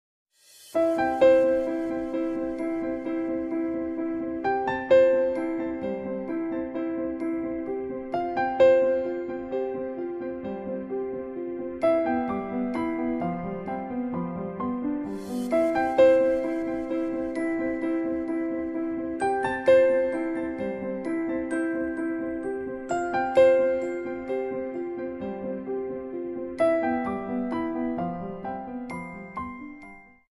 Kategoria Alarmowe